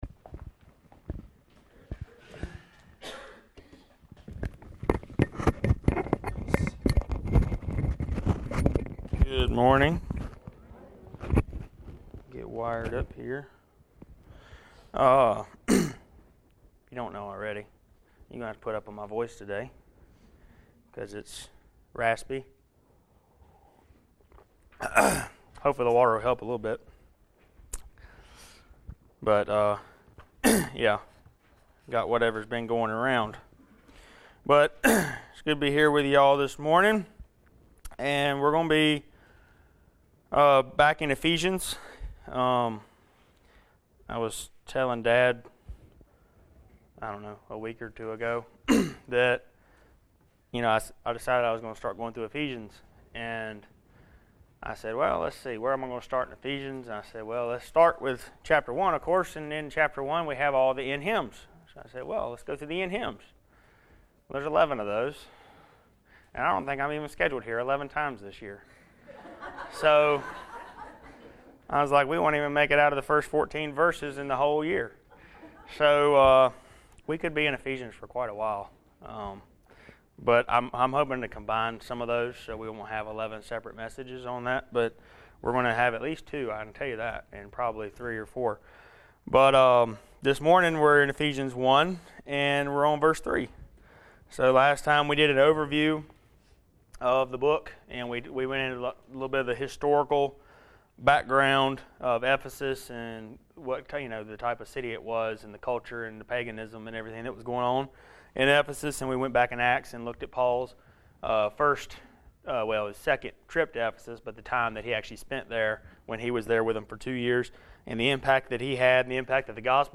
Ephesians Passage: Ephesians 1:3 Service Type: Sunday Morning Related « Back to the Basics